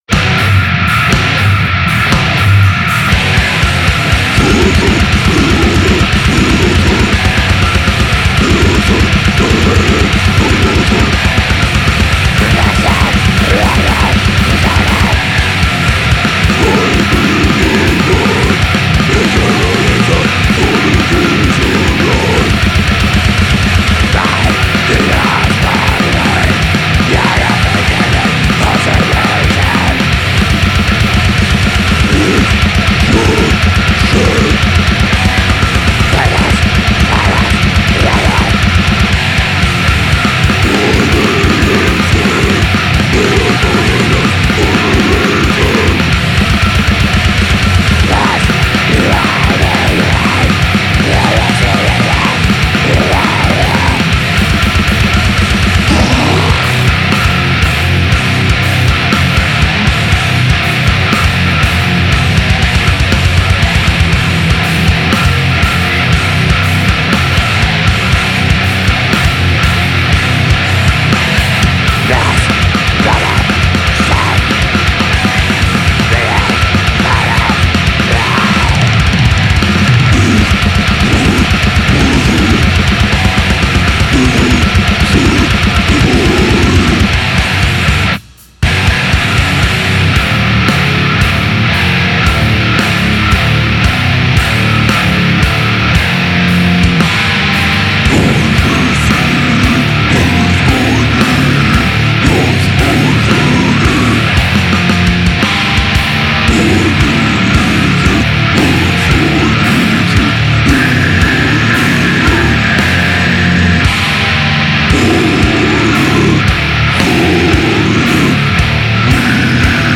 Ének